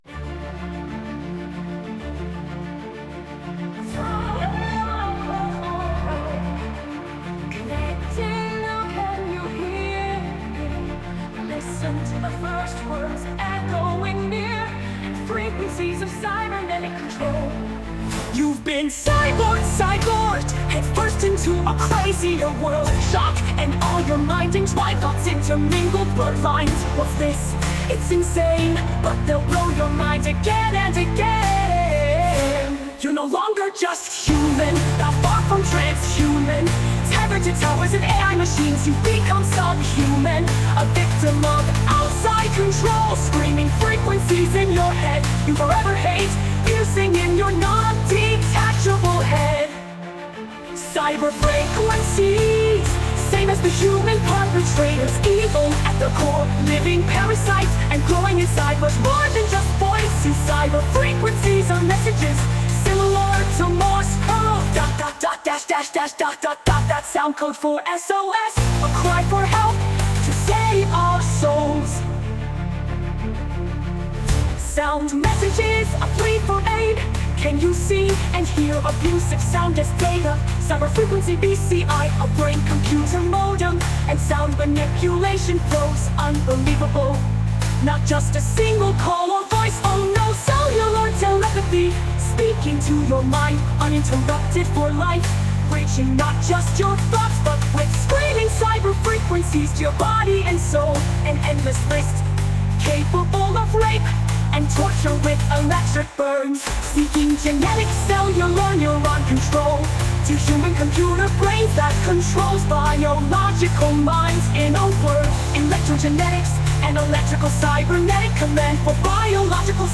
NOW TO GENERATE SOME MORE PROTEST MUSIC!